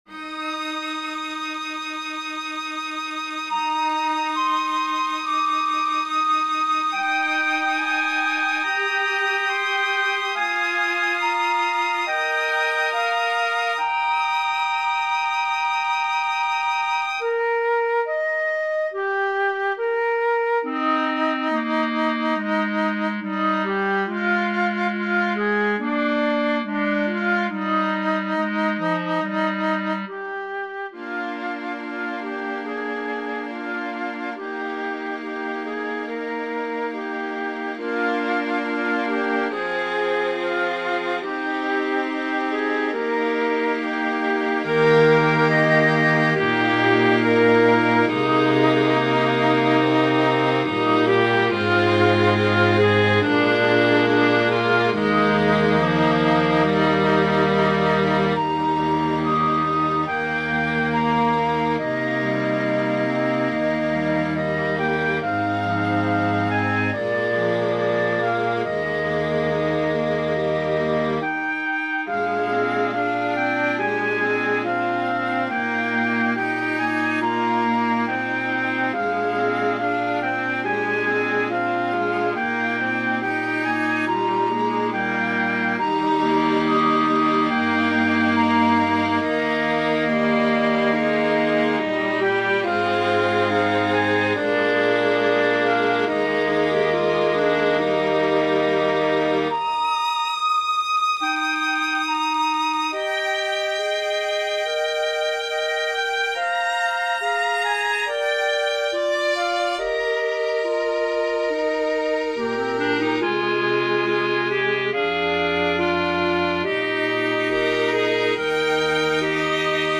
Flute,Clarinet,Violin,Viola,Cello